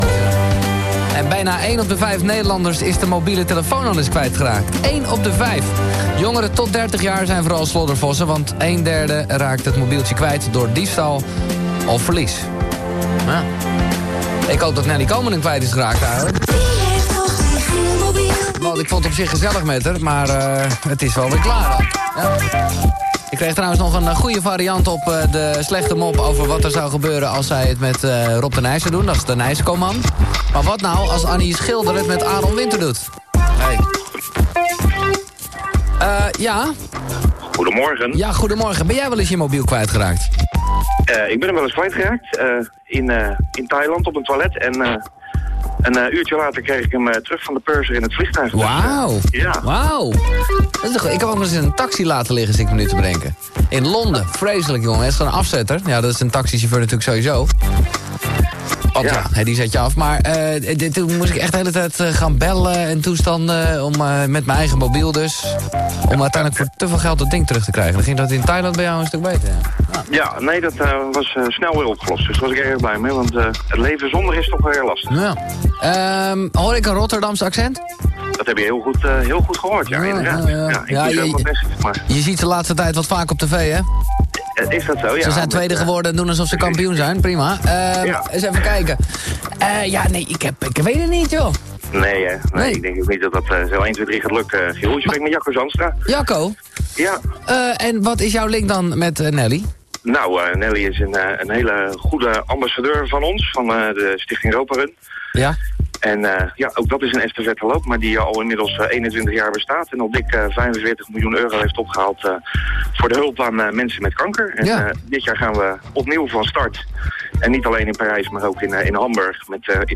hier het fragment van vanochtend wat is uitgezonden op radio 3FM terug!